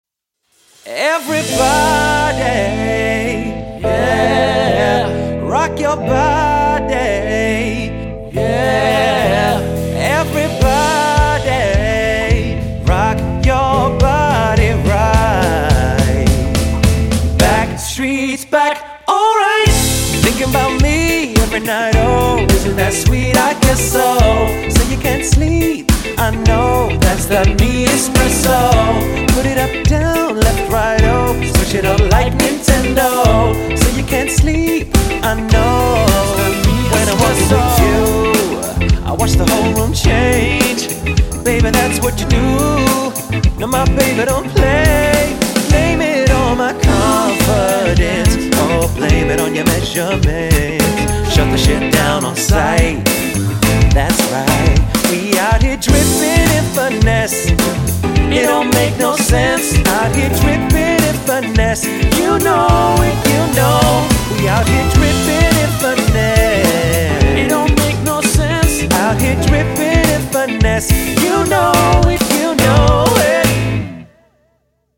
• Mix of modern and classic mashups for all ages
Vocals, Vocals/Guitar, Bass, Drums